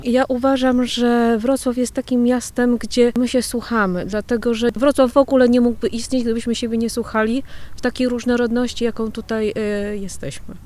Przy okazji Światowego Dnia Słuchania zapytaliśmy mieszkańców Wrocławia i osoby odwiedzające nasze miasto o opinię nt. tego, co może wpływać na zamykanie się na rozmowę z innym człowiekiem: